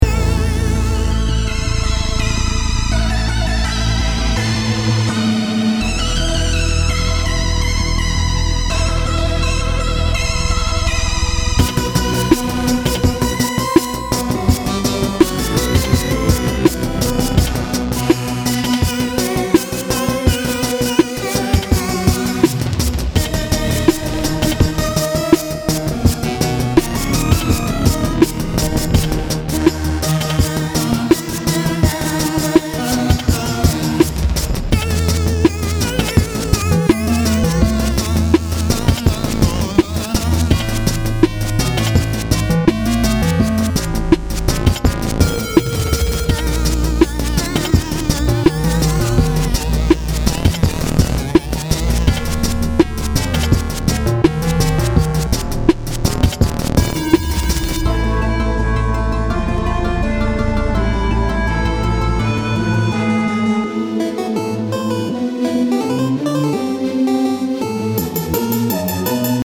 Sinister theme with a few variations.